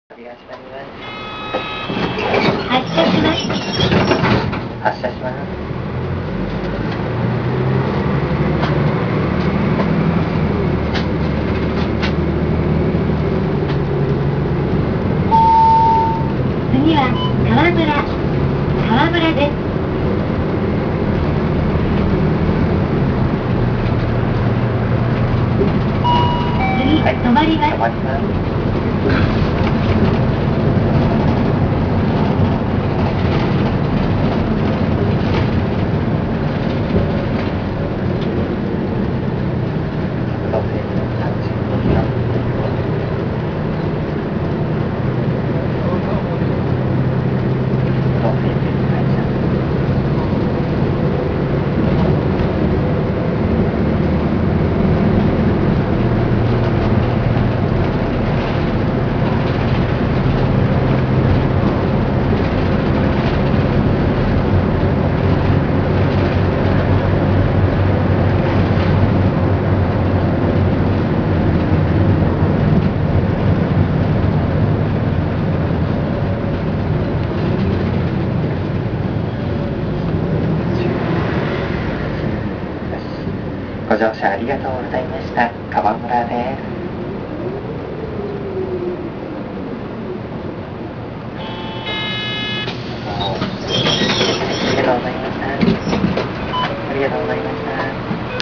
・GB-1000形走行音
もう他に言い様がないくらいバスの音です。
車内放送などもバスに準拠。運転手さんのアナウンスが丁寧なように感じますが、これも運転手さん次第なのでこの路線の特徴とは言えません。
1分38秒（535KB）　収録はG-57にて。